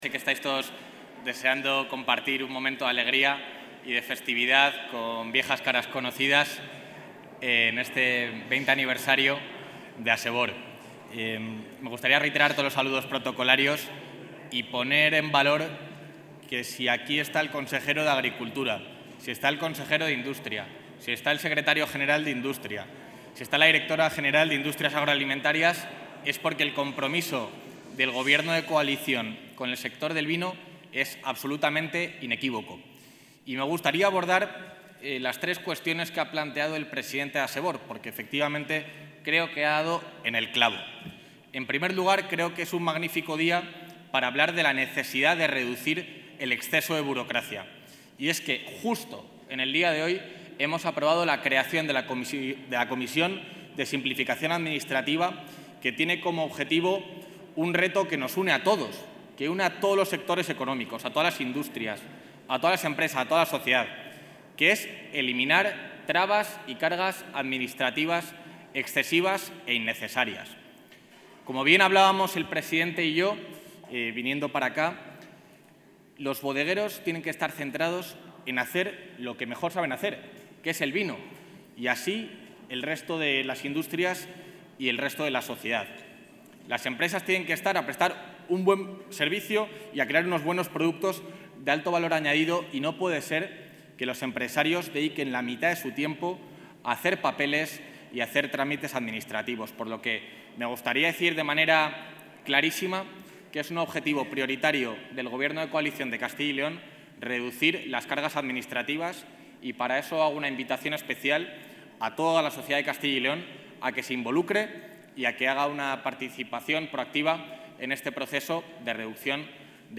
Intervención del vicepresidente de la Junta.
El vicepresidente de la Junta de Castilla y León, Juan García-Gallardo, ha presidido este jueves en la gala con la que la Asociación Empresarial de Bodegas adheridas a la Denominación de Origen Ribera del Duero (Asebor) ha celebrado su XX aniversario. Un acto que se ha celebrado en el Monasterio de Santa María de Valbuena, en San Bernardo (Valladolid), y en el que se han dado cita grandes y pequeñas bodegas con un amplio respaldo institucional.